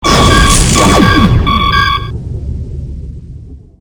shieldsfail.ogg